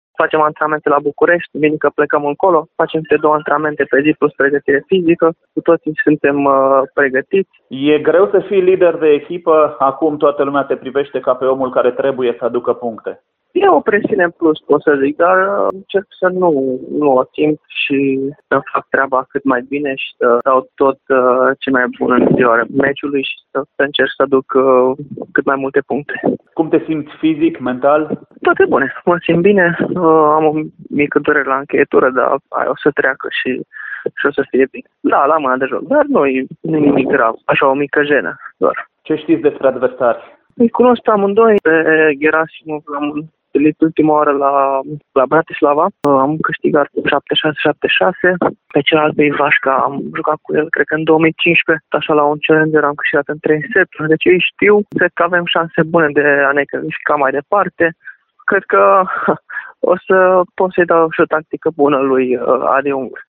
Cel mai bine clasat jucător de simplu din echipa noastră, Marius Copil (127 ATP) a vorbit pentru Radio Timișoara despre adversari. Bielorușii vor evolua la simplu cu Ilia Ivașka (177 mondial) și Egor Gerasimov (326 ATP), jucători pe care arădeanul i-a întâlnit în turnee challenger.